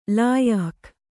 ♪ lāyahkh